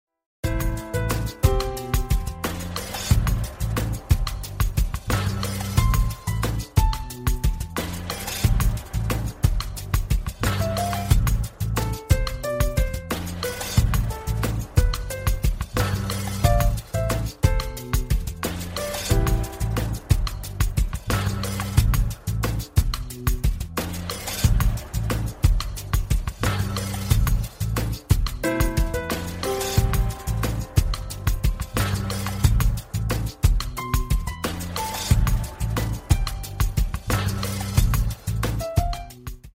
Спокойные И Тихие Рингтоны » # Рингтоны Без Слов
Рингтоны Электроника